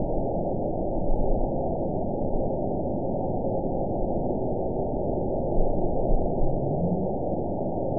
event 922898 date 05/02/25 time 00:03:29 GMT (1 month, 2 weeks ago) score 9.36 location TSS-AB10 detected by nrw target species NRW annotations +NRW Spectrogram: Frequency (kHz) vs. Time (s) audio not available .wav